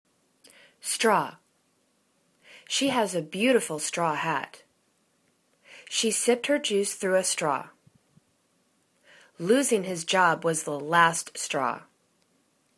straw     /straw/    n